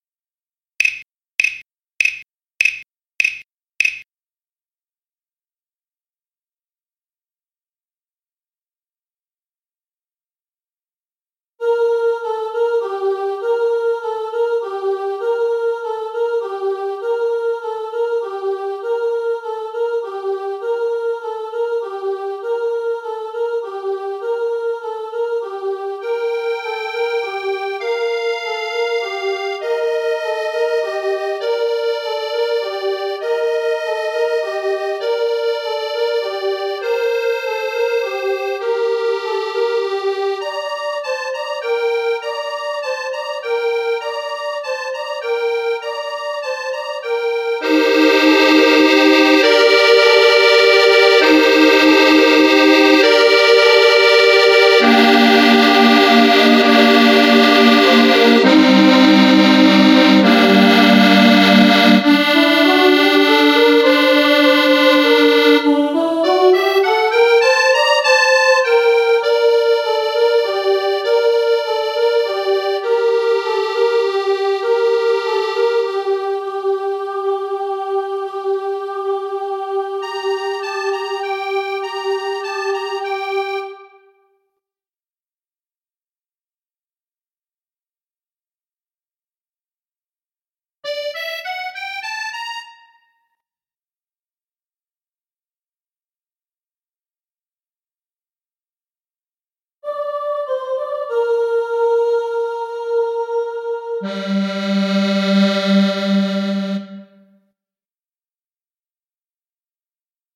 accordéon + voix :
carol-of-the-bells-accordeon-voix.mp3